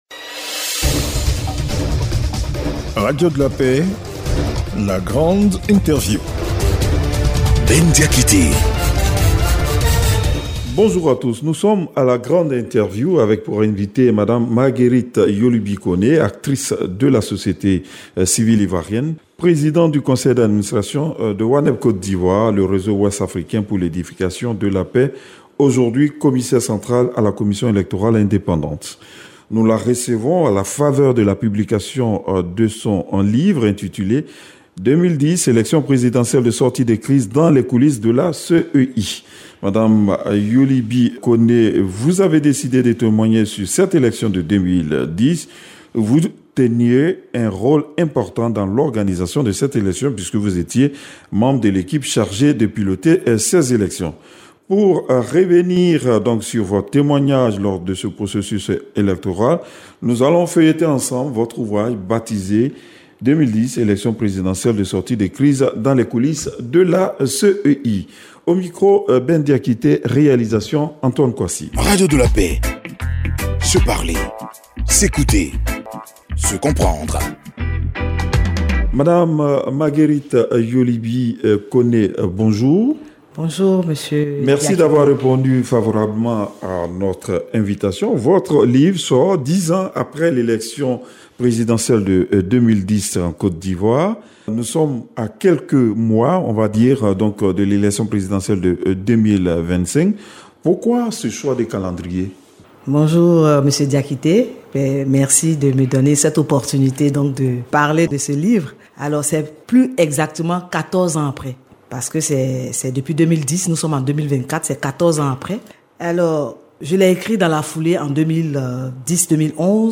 La Grande Interview – Madame Marguerite Yoli Bi Koné, Commissaire centrale à la CEI - Site Officiel de Radio de la Paix
la-grande-interview-madame-marguerite-yoli-bi-kone-commissaire-centrale-a-la-cei.mp3